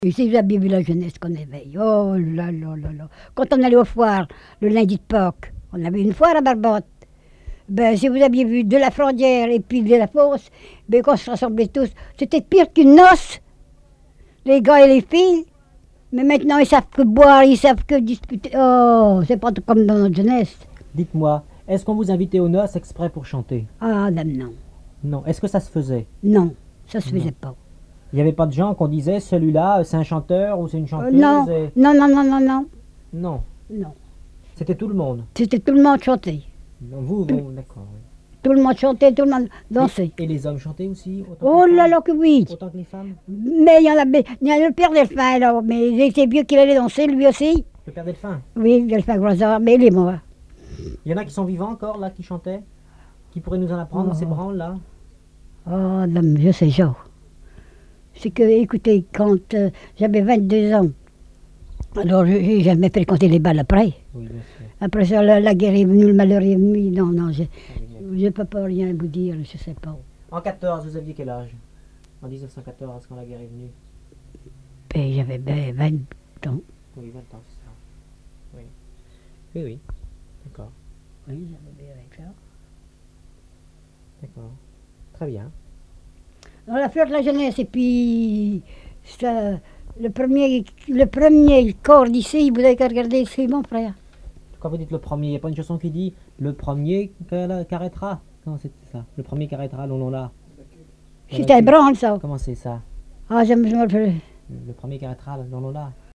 chanteur(s), chant, chanson, chansonnette
collecte en Vendée
Répertoire de chants brefs et traditionnels